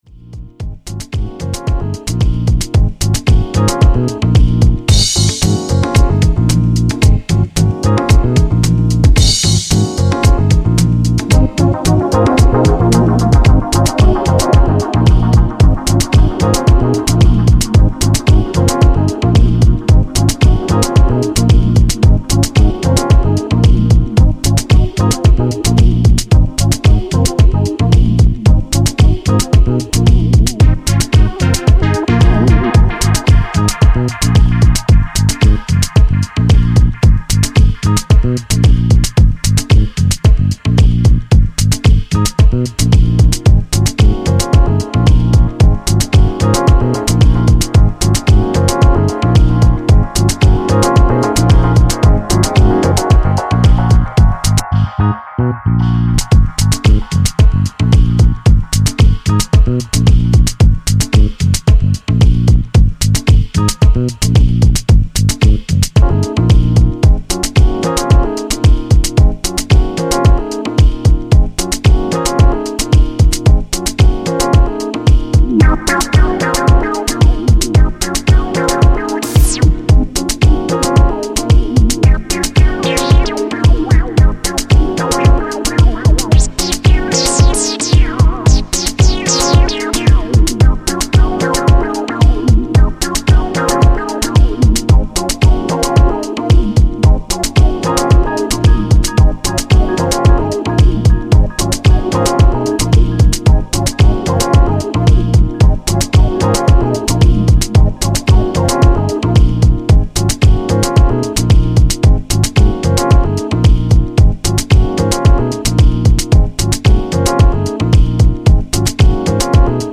exotic house!